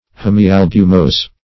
hemialbumose - definition of hemialbumose - synonyms, pronunciation, spelling from Free Dictionary
Search Result for " hemialbumose" : The Collaborative International Dictionary of English v.0.48: Hemialbumose \Hem`i*al"bu"mose`\, n. [Hemi- + albumose.]